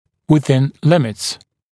[wɪ’ðɪn ‘lɪmɪts][уи’зин ‘лимитс]в пределах возможностей